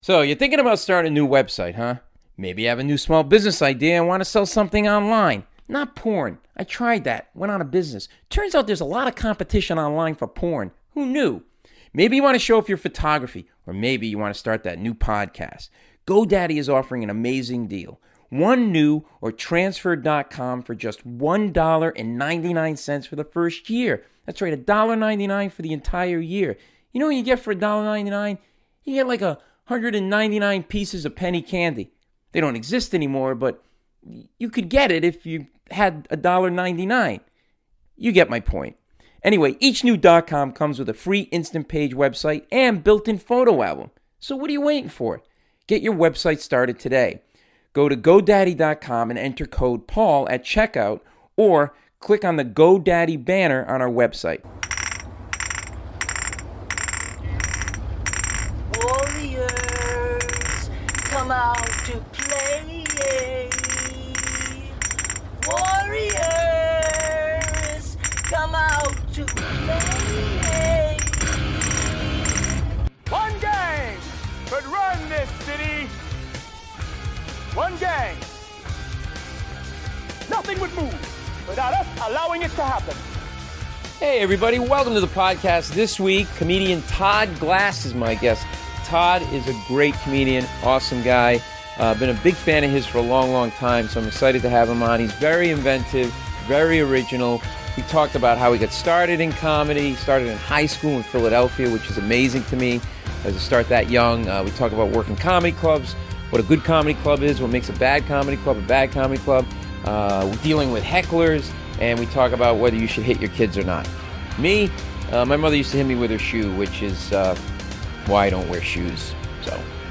Todd Glass (Paul Mecurio interviews Todd Glass; 09 Nov 2013) | Padverb